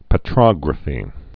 (pə-trŏgrə-fē)